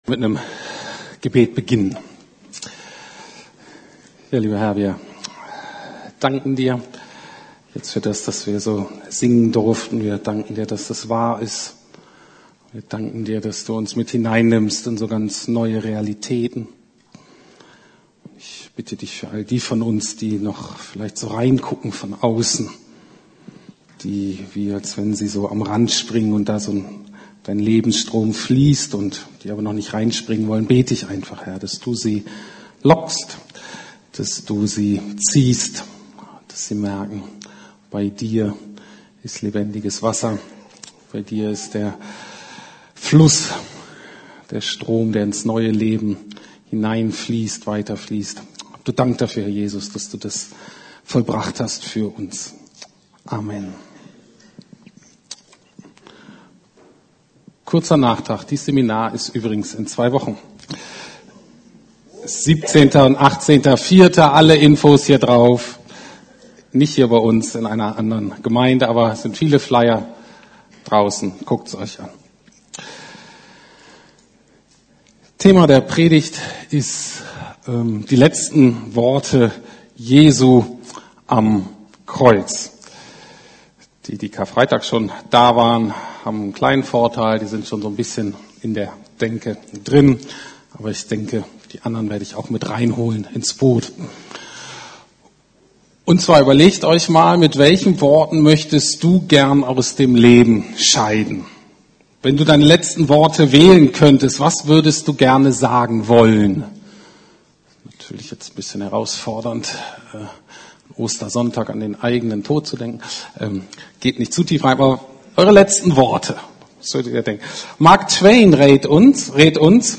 Jesu letzte Worte am Kreuz ~ Predigten der LUKAS GEMEINDE Podcast